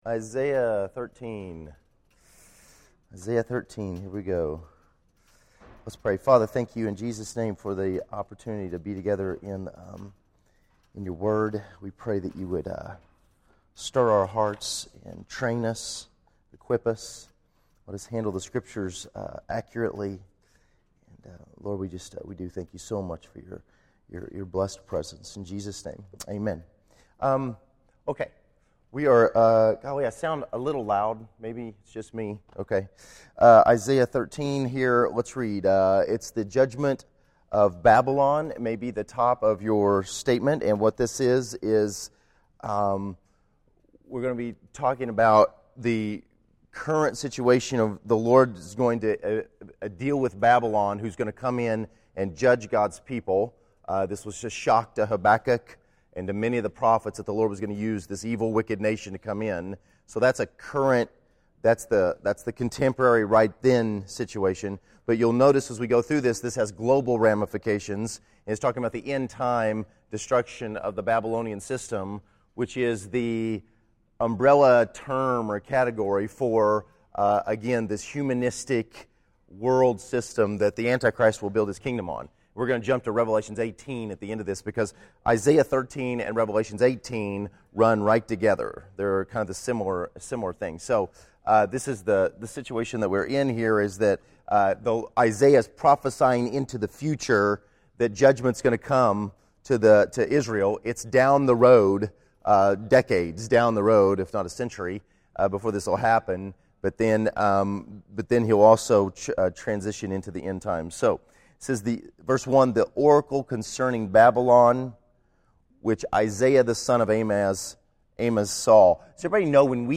Sunday School